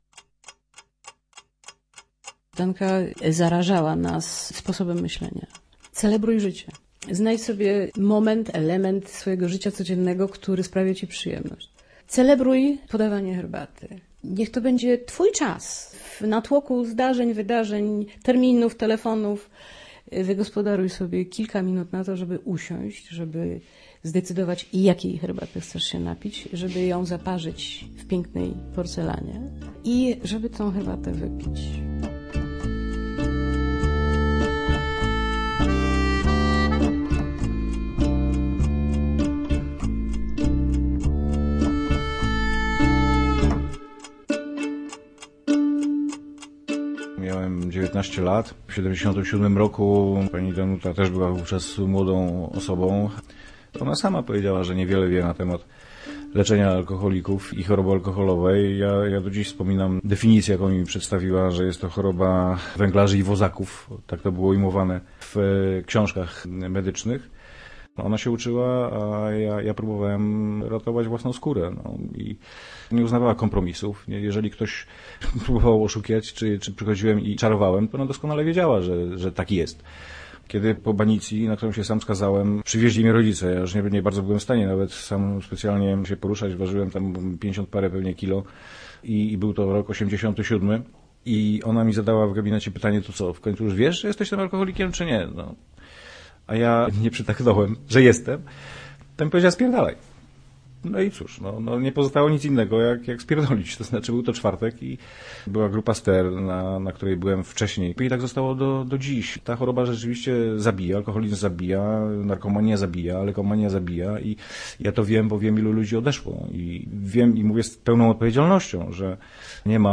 reportaż